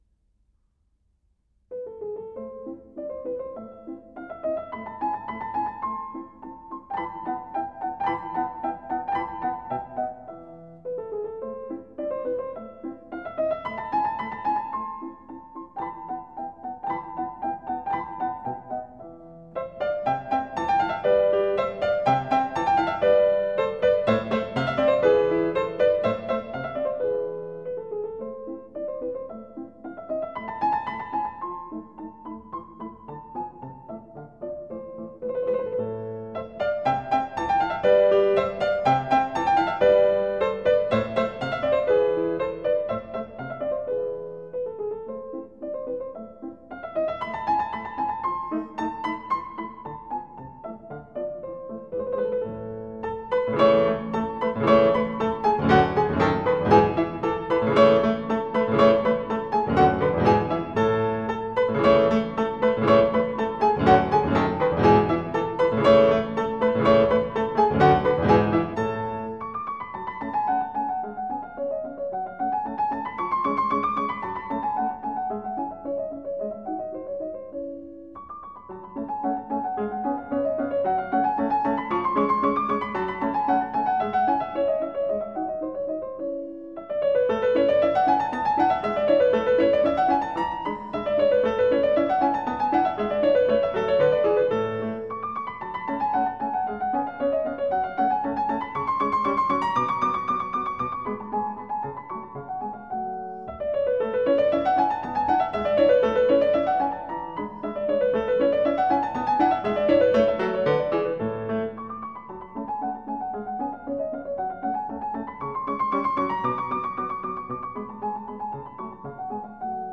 Allegretto